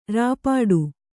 ♪ rāpāḍu